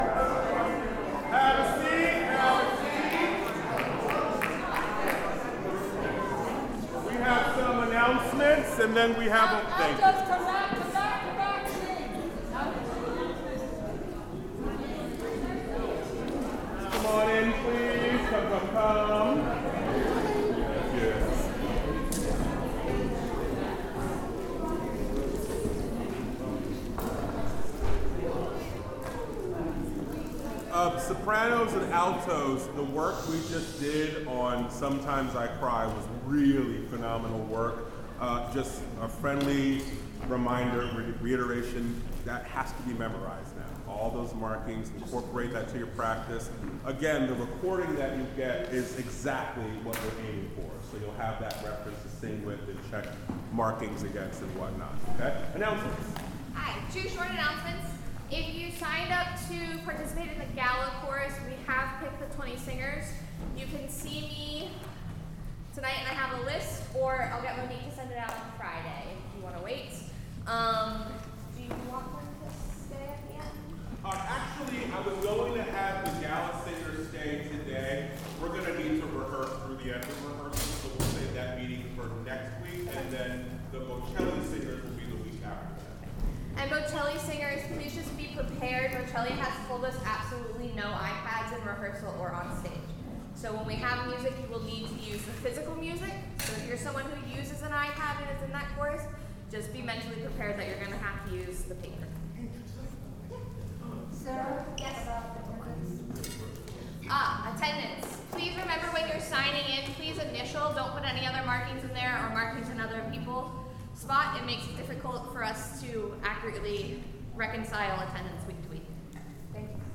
OSC Rehearsal, April 16, 2025
Parts of this rehearsal were sectionals which were not recorded.
Tenors and basses have sectionals.